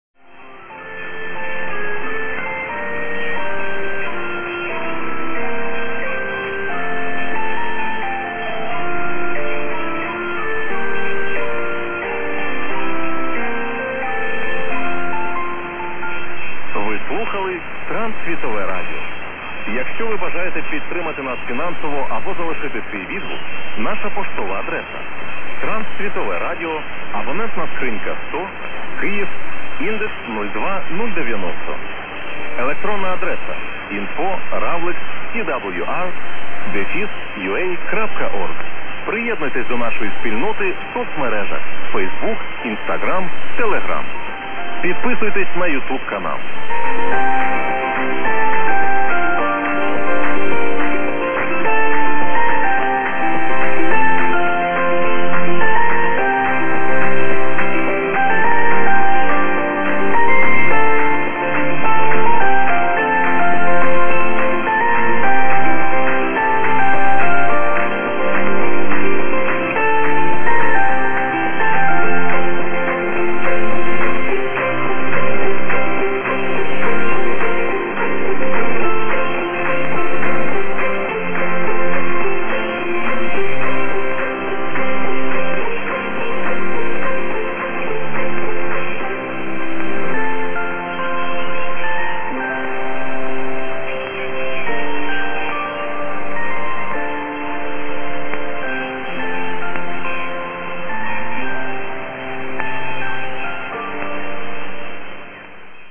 ARMENIA, TWR Europe at 1377 KHz. Despite the solar maximum, I could hear during a quiet period, the TWR relay in Jan 2024 here,